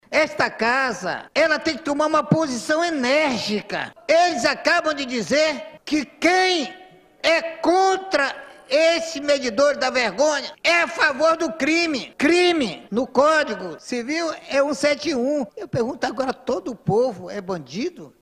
O deputado estadual, Sinésio Campos (PT), tratou do assunto na tribuna.